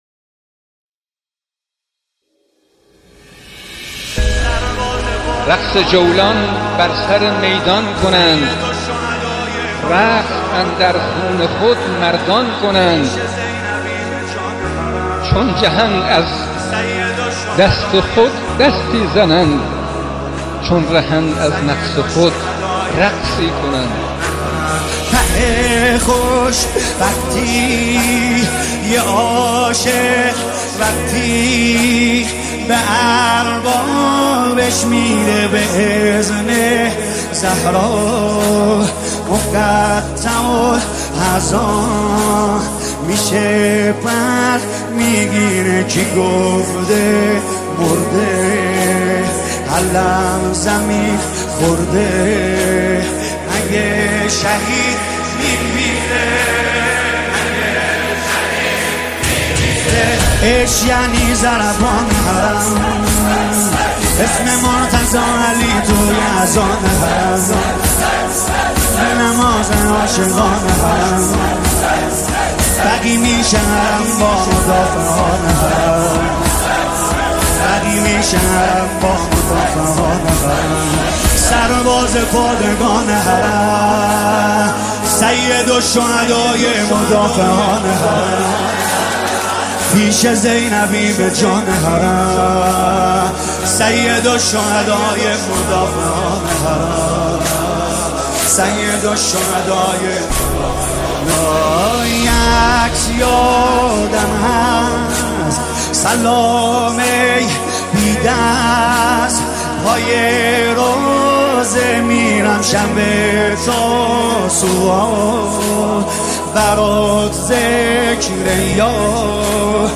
مداحی محرم